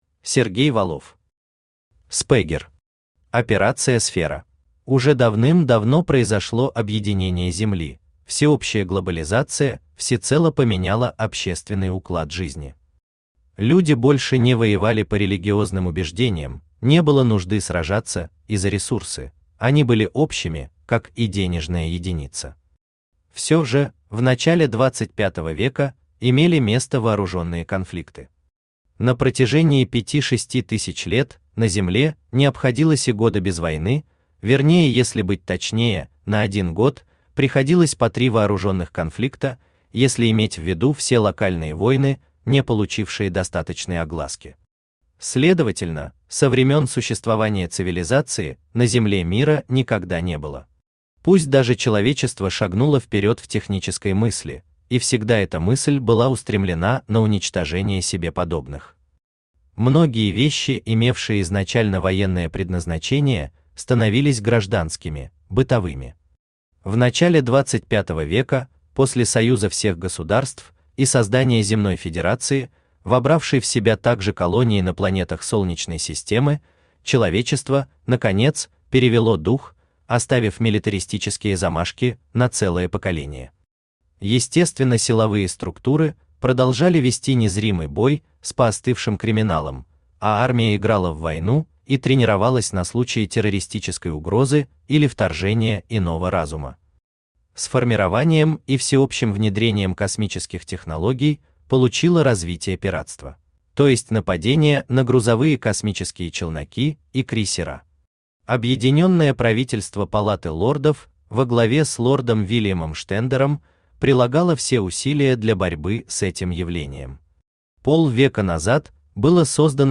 Аудиокнига Спэгер.
Aудиокнига Спэгер. Операция Сфера Автор Сергей Викторович Валов Читает аудиокнигу Авточтец ЛитРес.